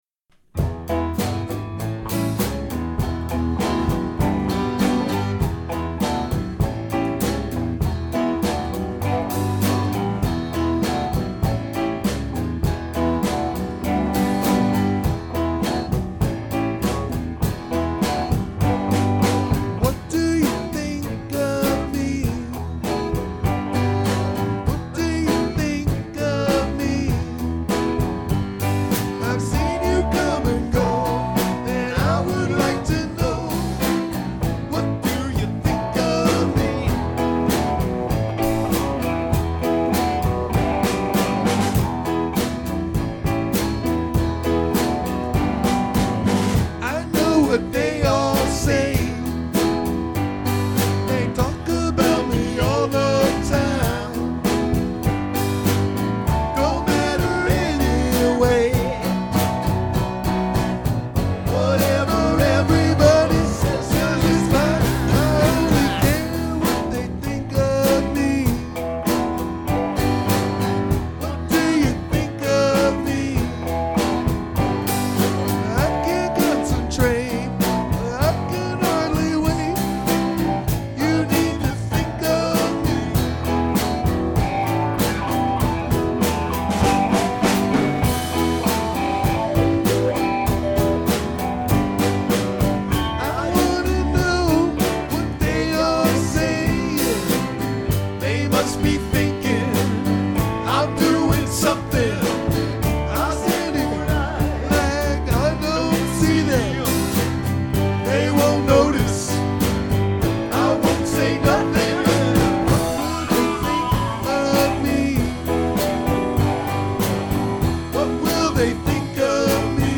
guitar, bass
drums
RECORDINGS with the full band!